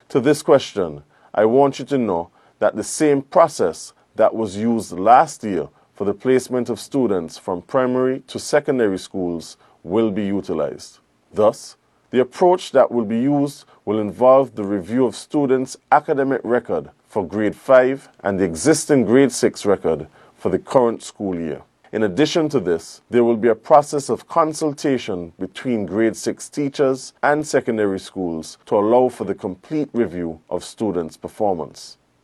Mr. Powell in a recent statement to the nation, provided this response: